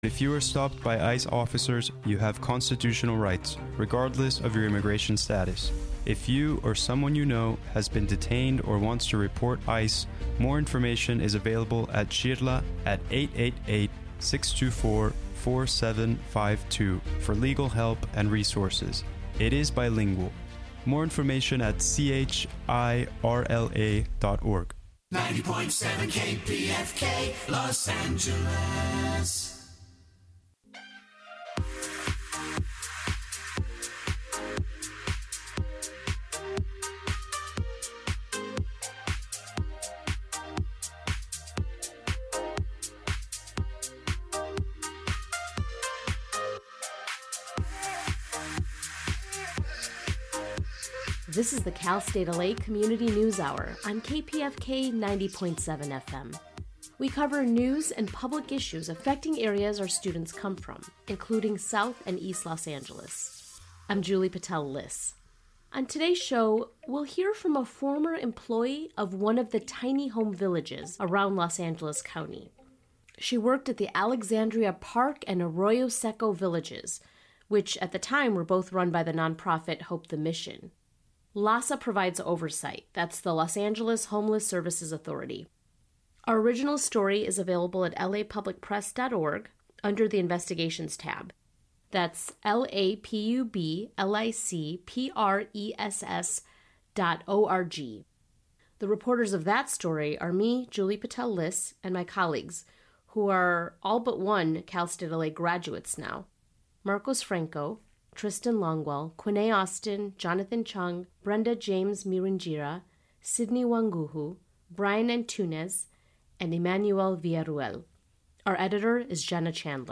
Beneath the Surface features interviews with leading thinkers and activists on the important issues of the day, with a focus on deeper analysis.